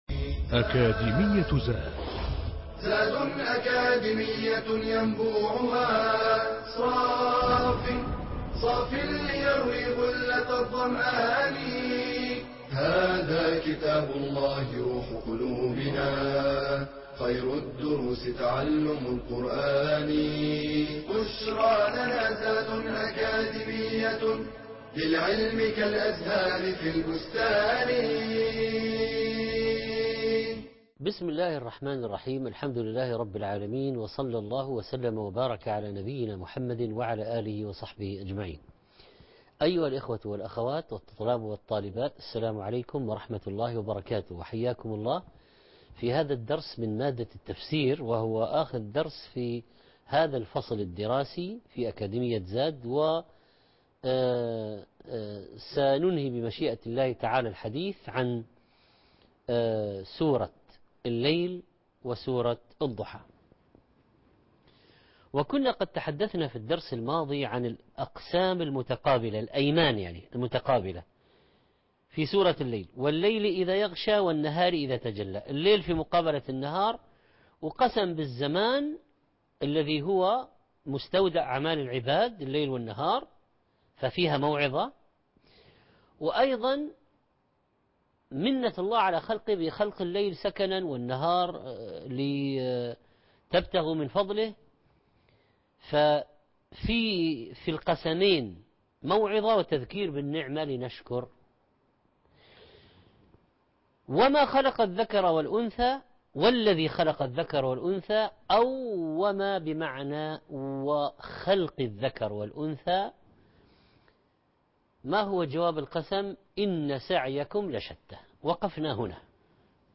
المحاضرة الرابعه والعشرون - سورة الليل ( 26/4/2017 ) التفسير - الشيخ محمد صالح المنجد